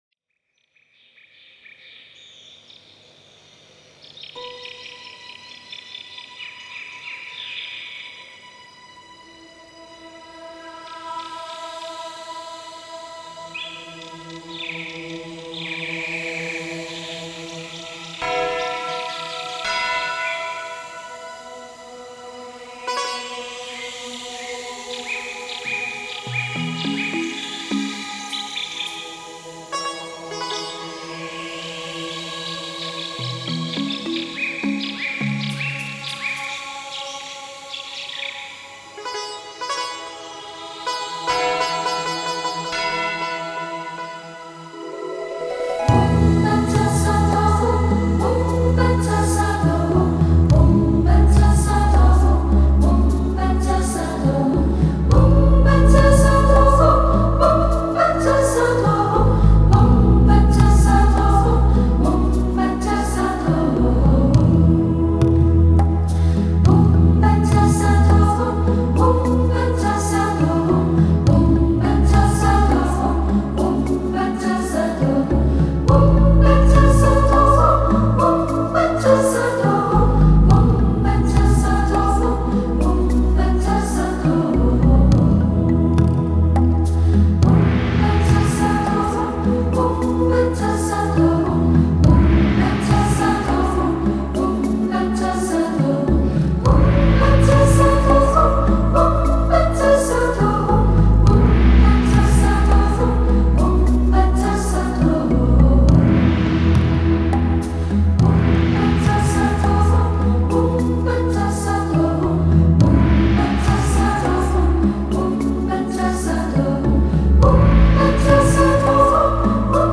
[20/8/2010]北京快乐小菩萨合唱团唱诵：《金刚萨埵心咒》